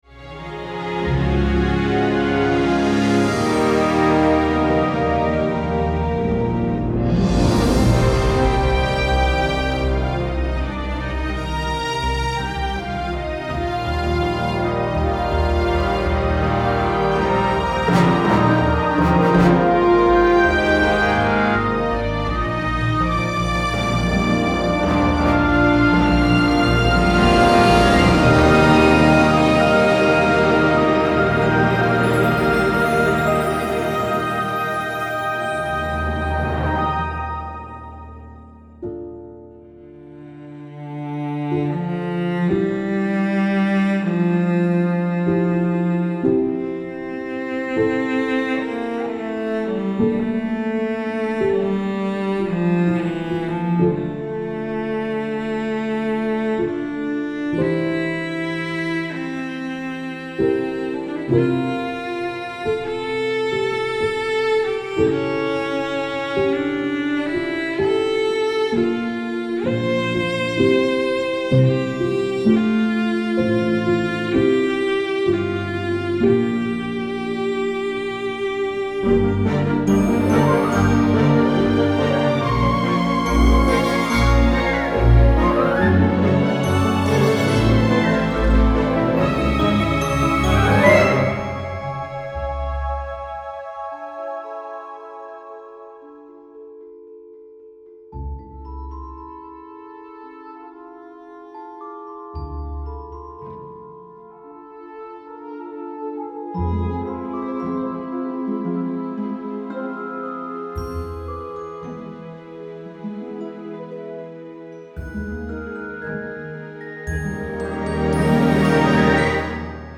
Film Scoring Demo (multiple genres)